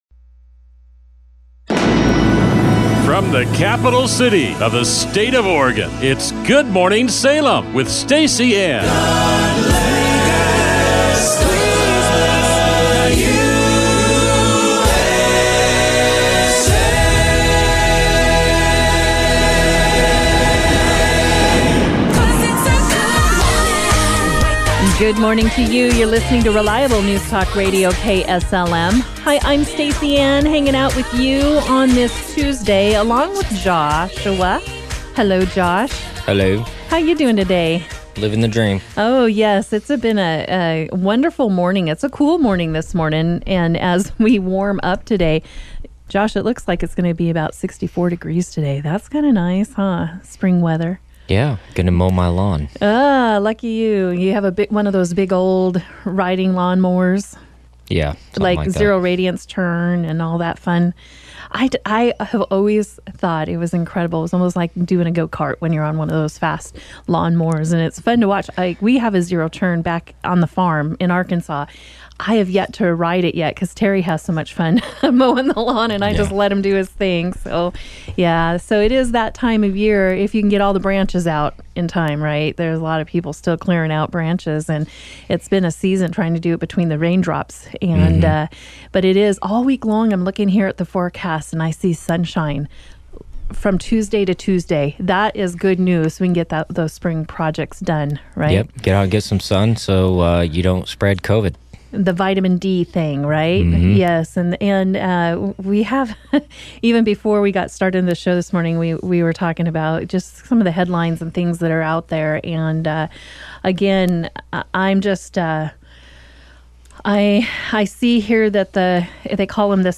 Several callers calling in with their "Mask" stories.